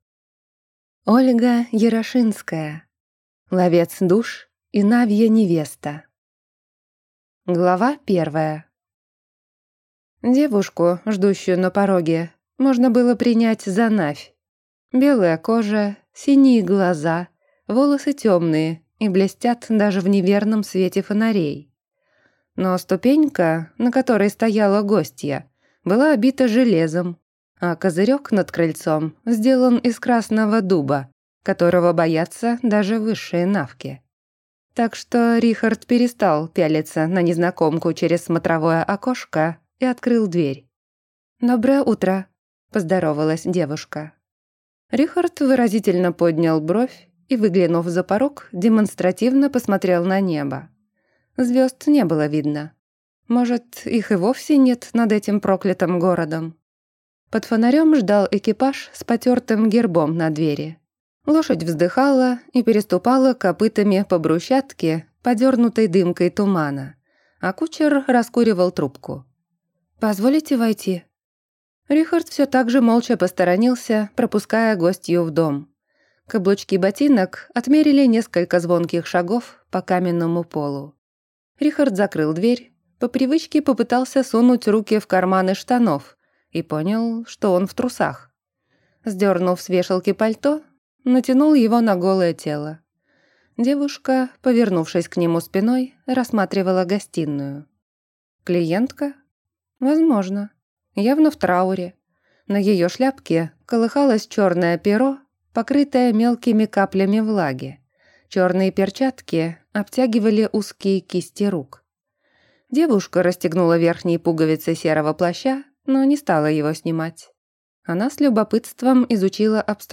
Аудиокнига Ловец душ и навья невеста | Библиотека аудиокниг